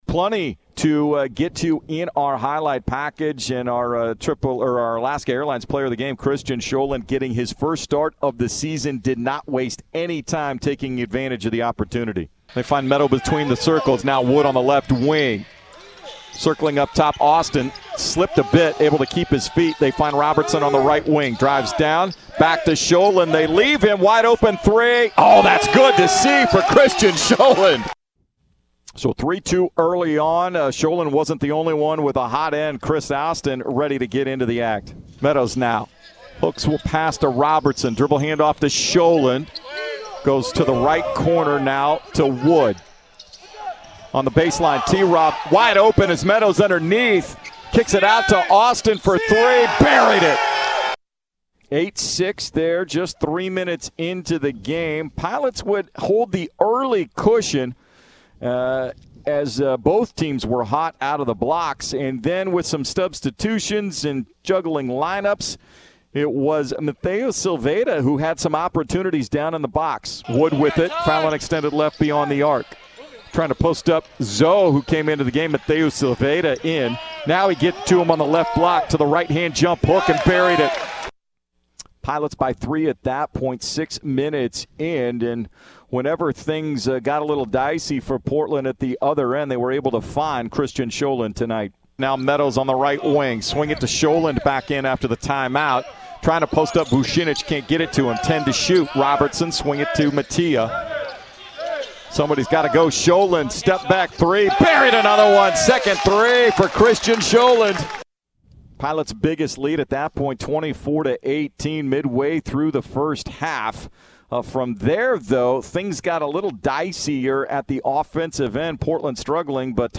Radio highlights from Portland's 78-77 win over Cal Poly at the Chiles Center on Dec. 13.